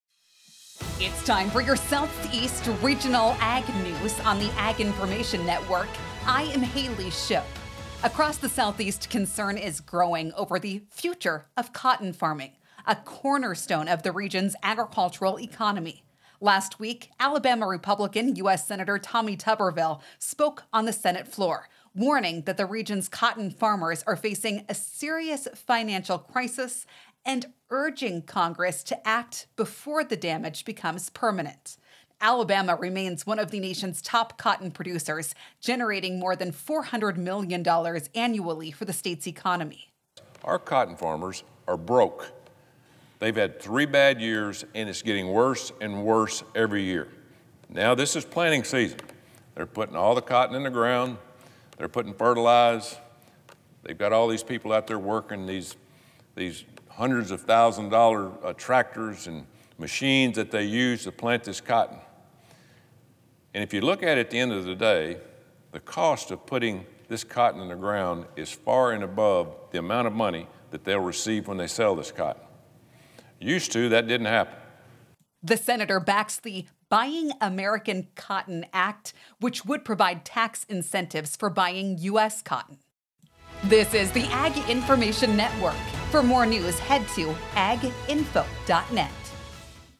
Across the Southeast, concern is growing over the future of cotton farming, a cornerstone of the region’s agricultural economy. Last week, Alabama Republican U.S. Senator Tommy Tuberville spoke on the Senate floor, warning that the region’s cotton farmers are facing a serious financial crisis and urging Congress to act before the damage becomes permanent.